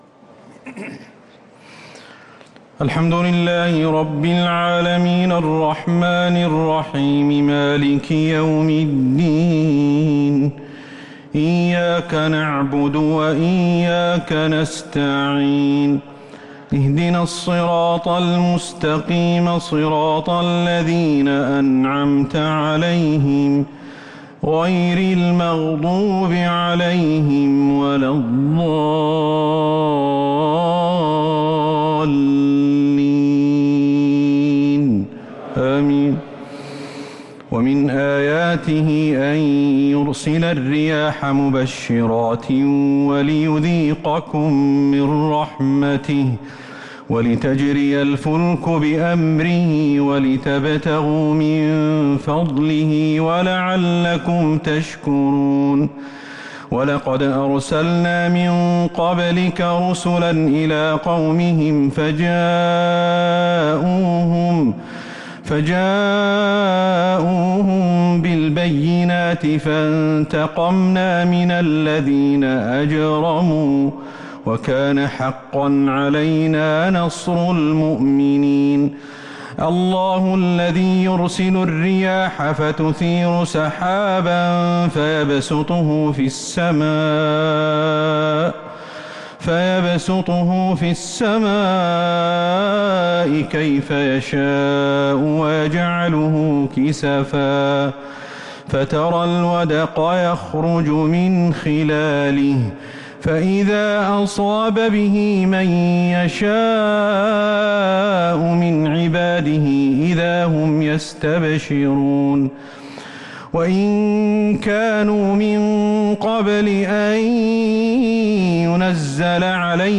التسليمتان الأخيرتان صلاة التراويح